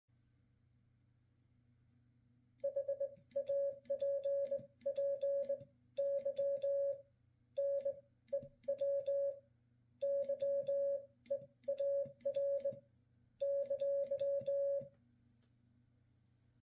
Happy New Year! Morse Sound Effects Free Download
Happy New Year! - Morse 16 seconds 5 Downloads Merry Christmas!